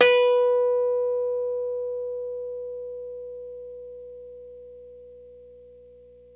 RHODES CL0ER.wav